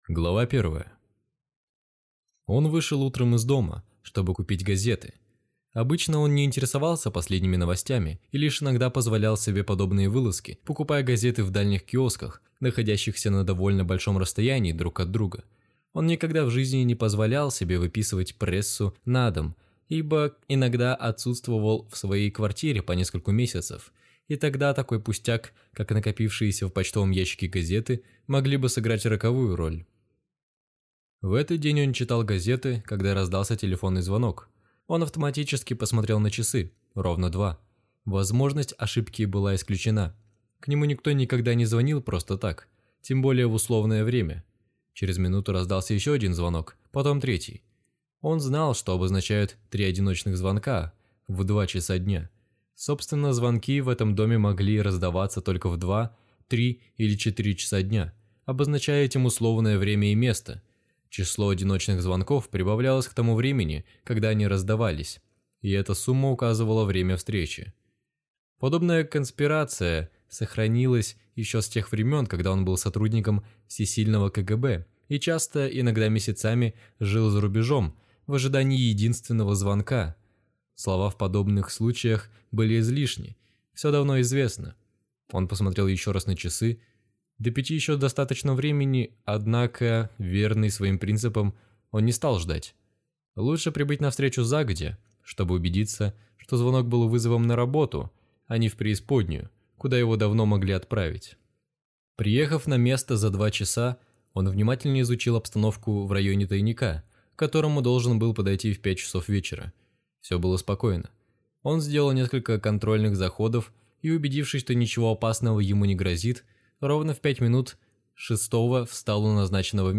Аудиокнига Сила инерции | Библиотека аудиокниг